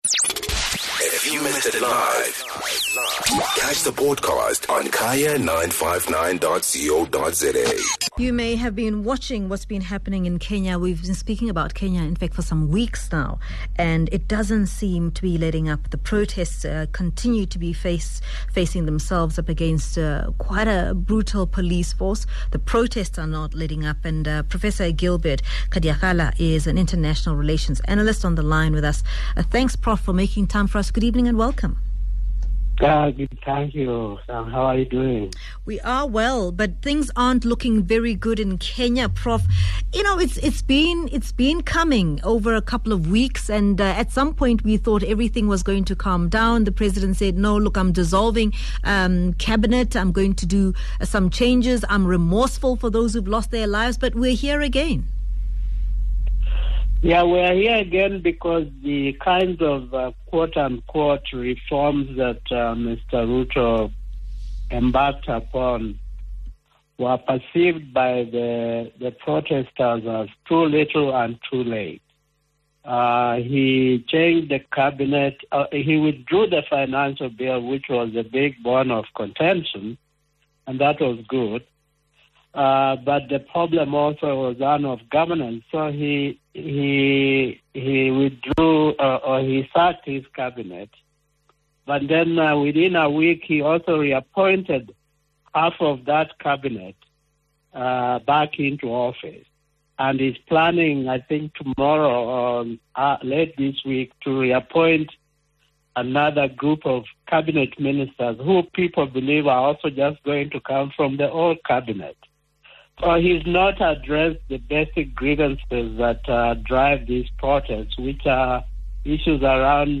International Relations Expert